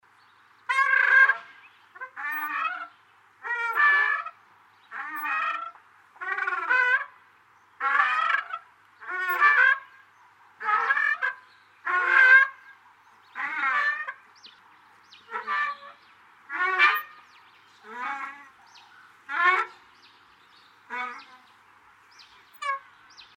Звук качели на легком ветру